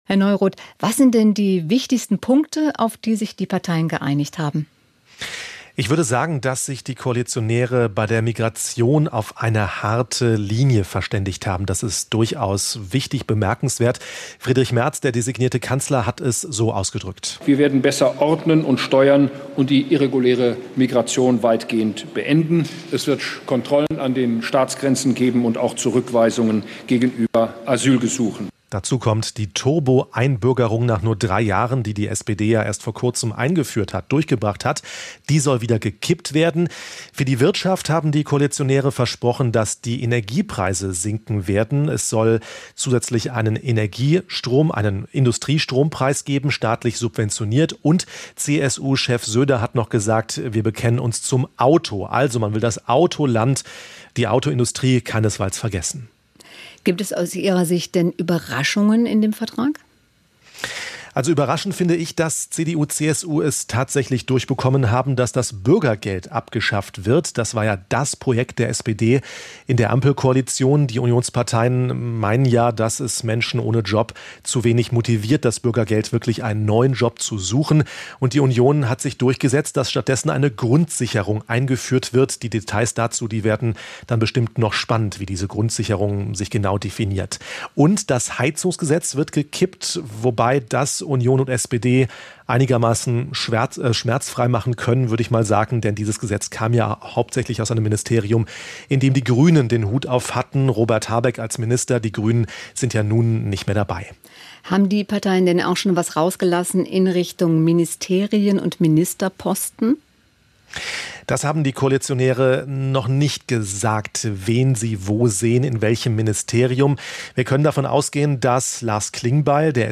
Topthemen aus Politik, Wirtschaft, Wissenschaft und Sport: Wir ordnen ein, wir klären auf, wir bohren nach. "SWR Aktuell Im Gespräch" - das sind Interviews mit Menschen, die etwas zu sagen haben.